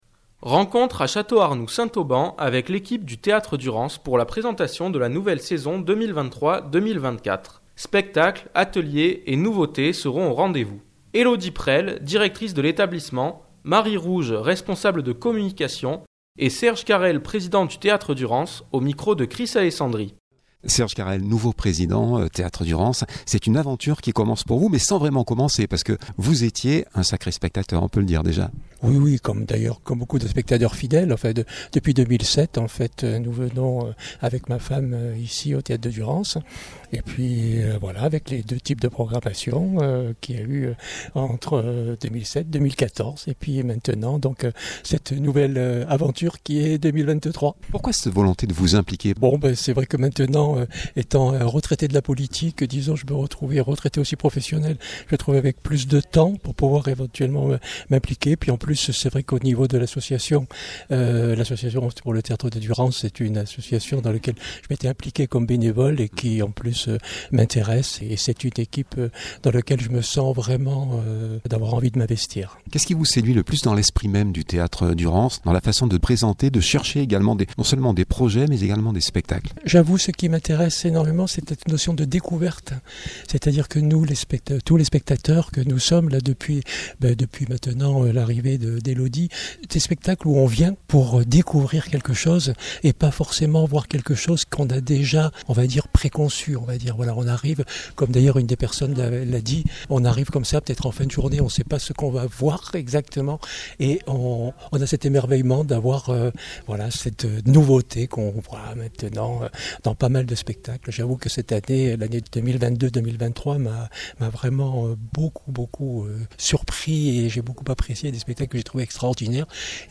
2023-07-03 - Chateau arnoux Theatre Durance saison 23 24.mp3 (12.22 Mo) Rencontre à Château-Arnoux Saint-Auban avec l’équipe du Théâtre Durance pour la présentation de la nouvelle saison 2022 /2024. Spectacles, ateliers et nouveautés sont au rendez-vous…